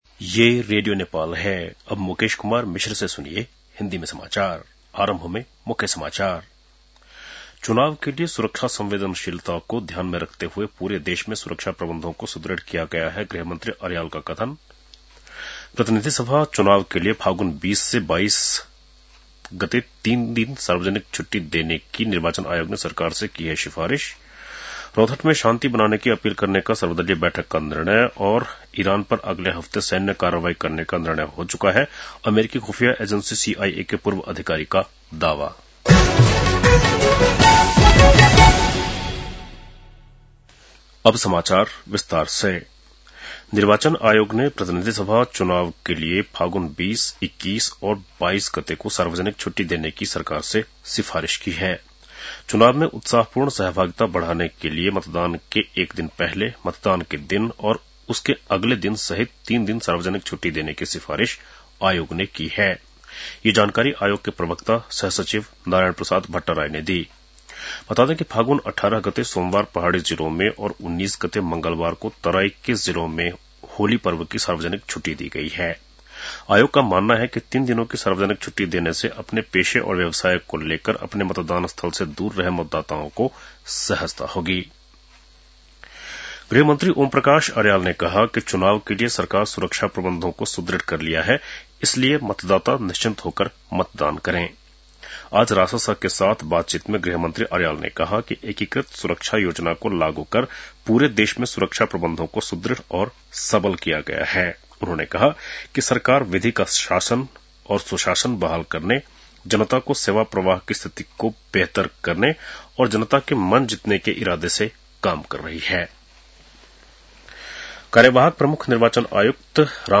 बेलुकी १० बजेको हिन्दी समाचार : १० फागुन , २०८२